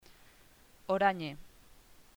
ApelativoORANJEOráñe